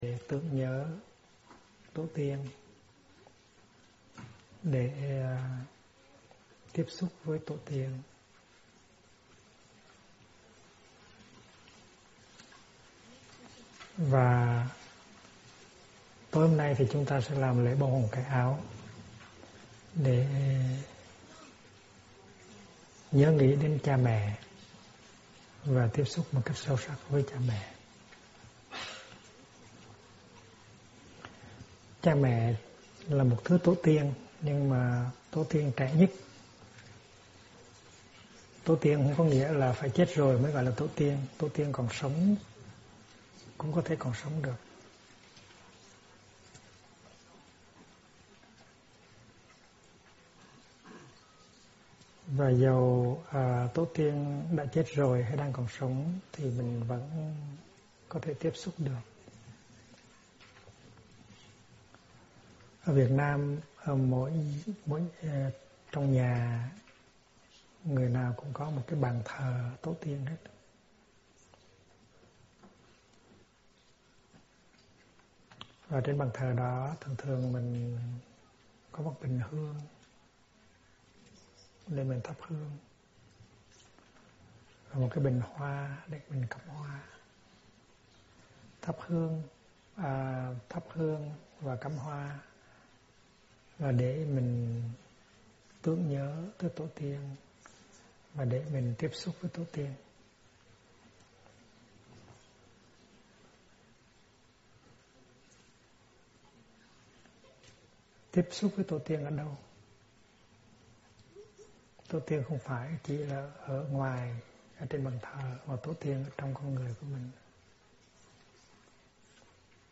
Mời quý phật tử nghe mp3 thuyết pháp Bàn tay mẹ do HT. Thích Nhất Hạnh giảng